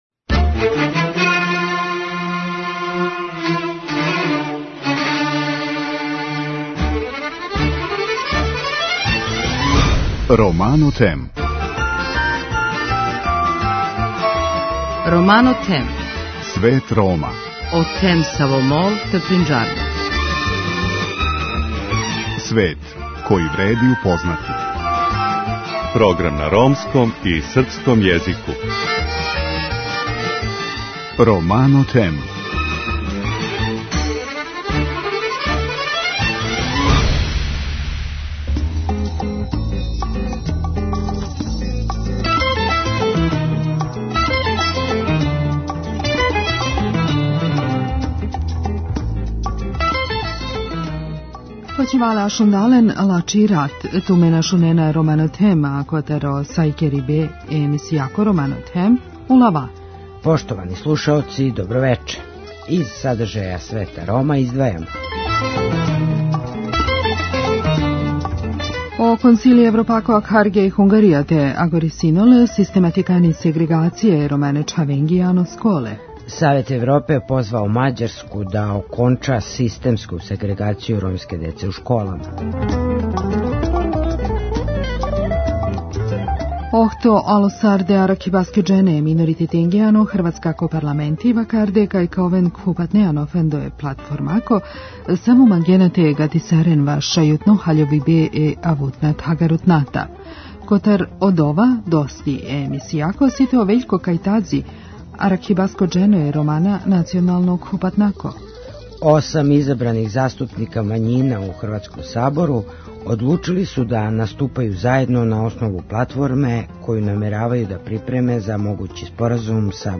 Осам изабраних заступника мањина у хрватском Сабору одлучили су да наступају заједно на основу платформе коју намеравају да припреме за могући споразум са будућом владом. Тим поводом гост емисије је Вељко Кајтази, заступник ромске националне заједнице.